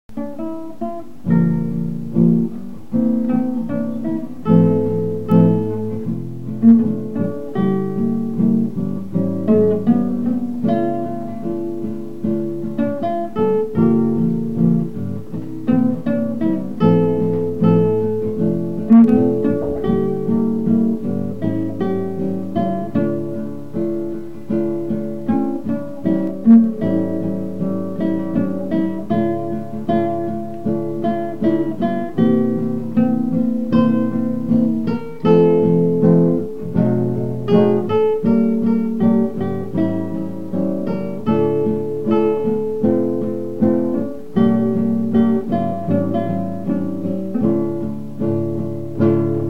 Duo de Guitarras
La verdad es que sonaba bastante bien.